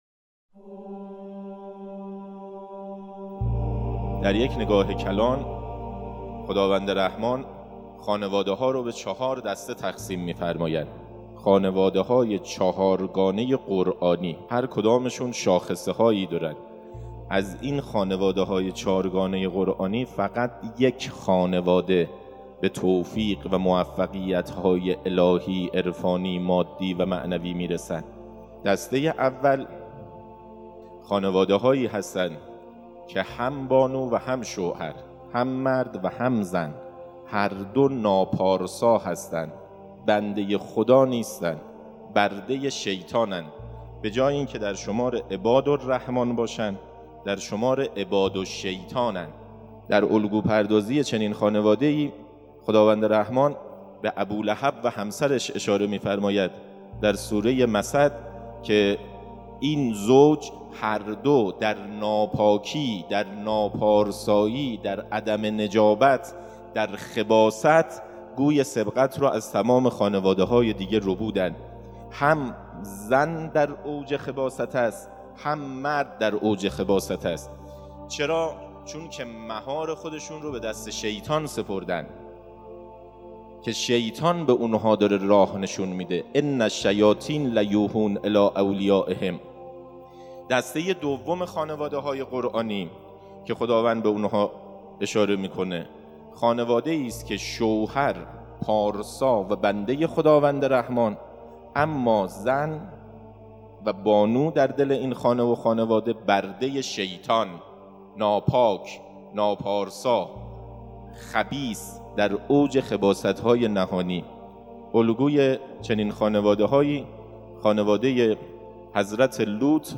فرازی از سخنرانی